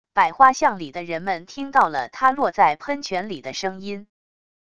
百花巷里的人们听到了他落在喷泉里的声音wav音频生成系统WAV Audio Player